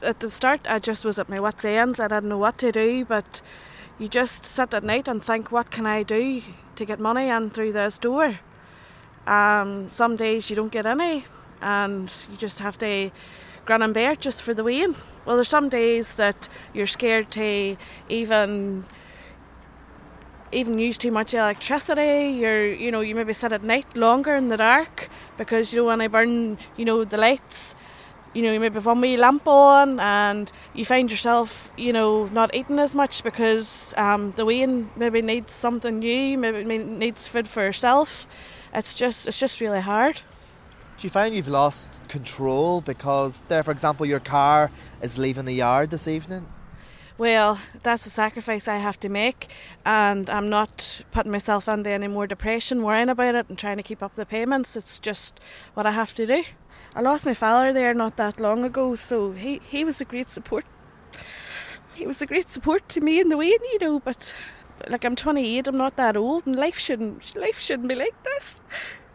Here is an shortened edited version of her interview.